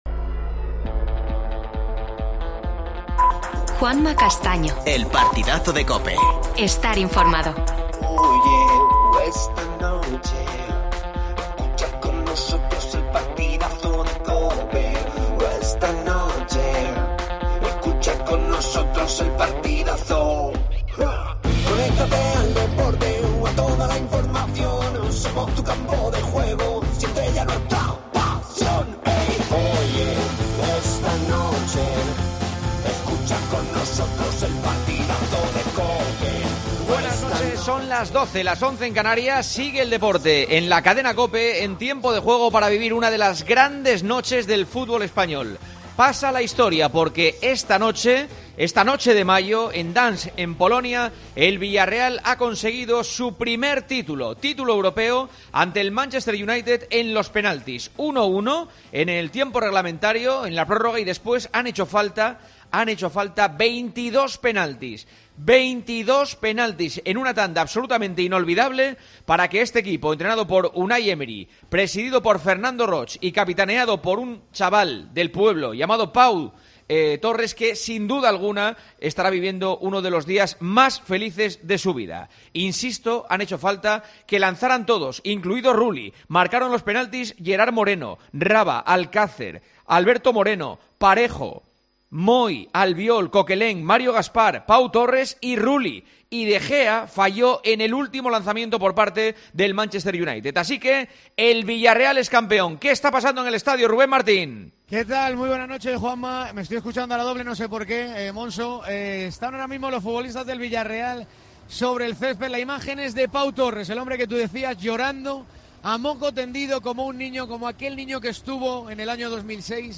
Entrevista a Fernando Roig. Vivimos en directo la entrega del trofeo.